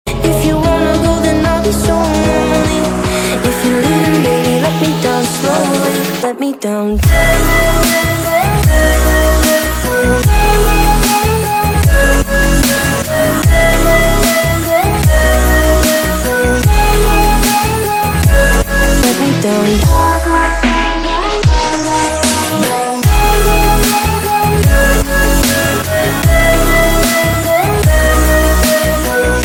soulful R&B track